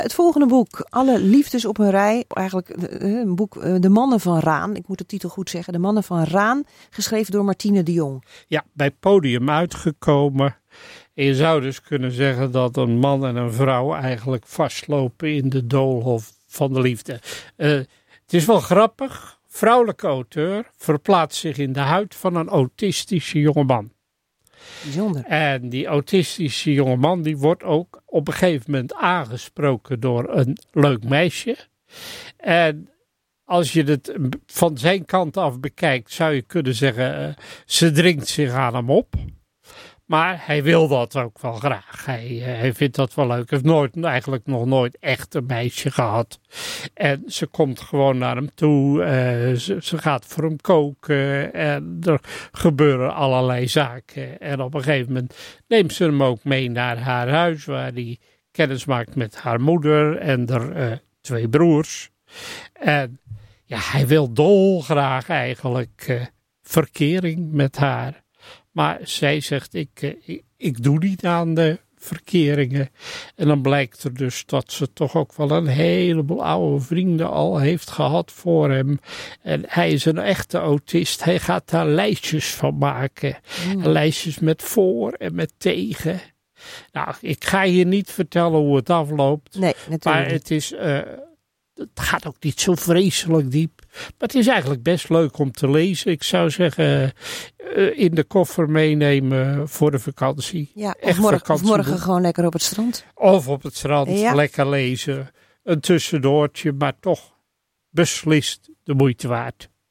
Dit boek is tevens op donderdag 2 juli 2015 besproken in het programma MeerInformatief van MeerRadio.